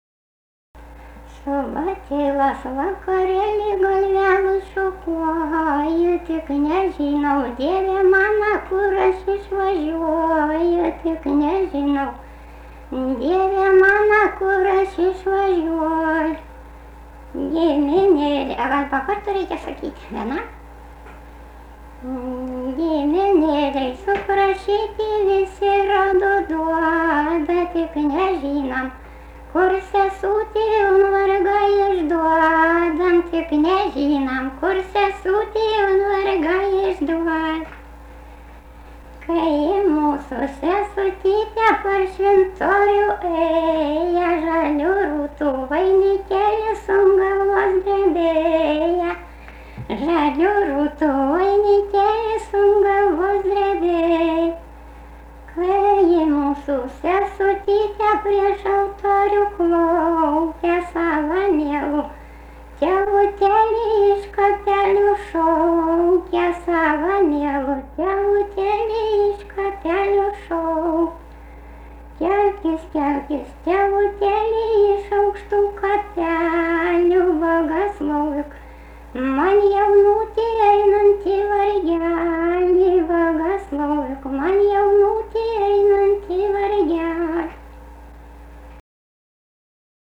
daina, vestuvių
Kazimierava
vokalinis